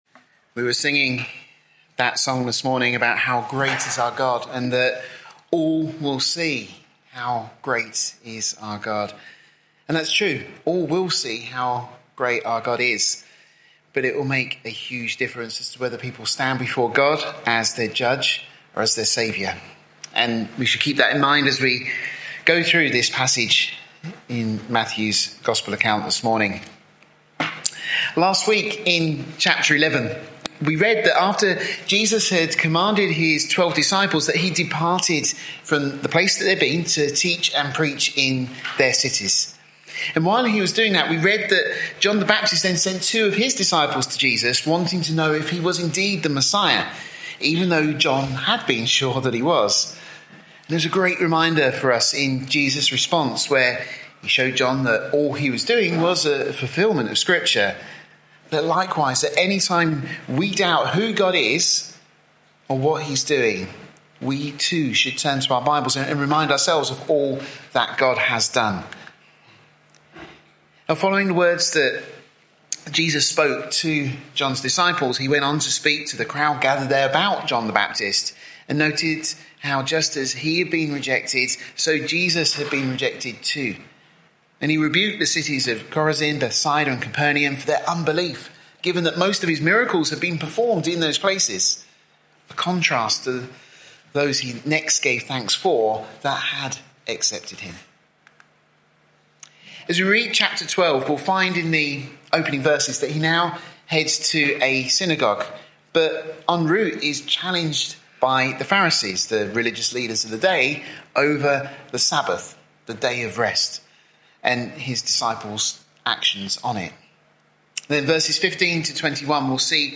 This study is part of our series of verse by verse studies of Matthew, the 40th book in the Bible.